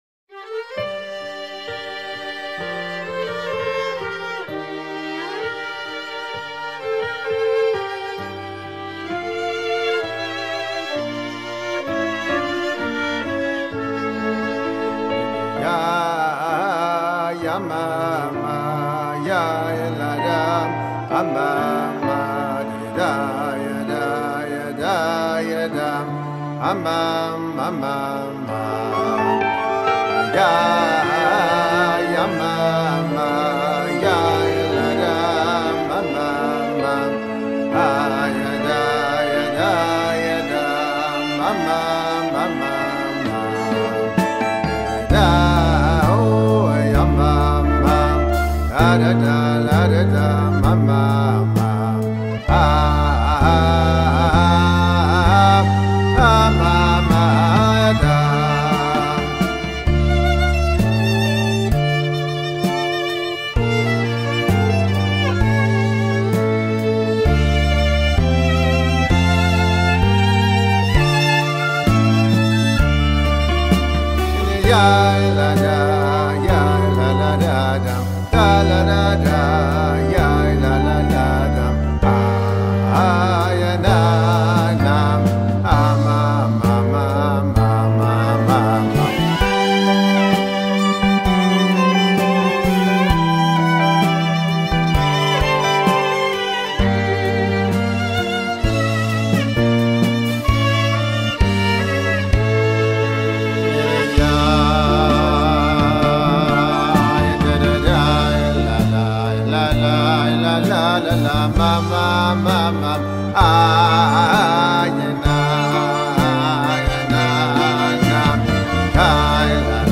ניגוניו מצטיינים בביטוים חריפים של עמקות המחשבה כשהיא אחוזה וקשורה בהתרגשות הלב והתפעלות הלב.